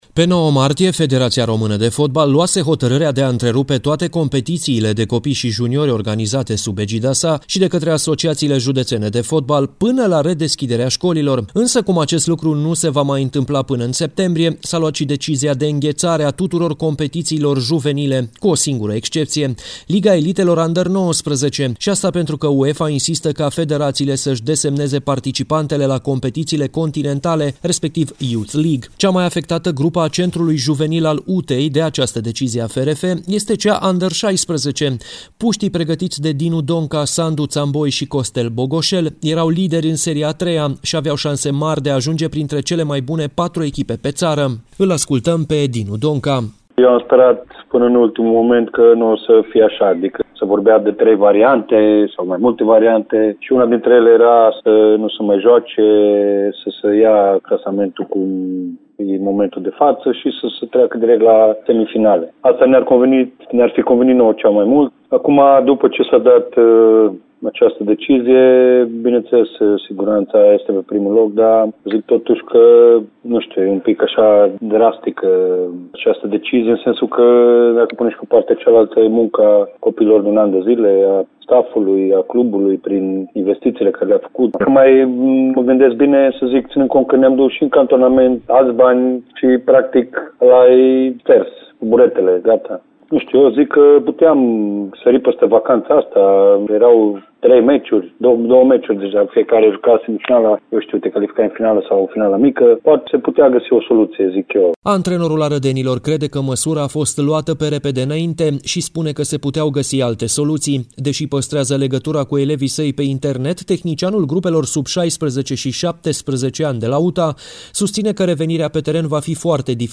Reportajul despre juniorii UTA-ei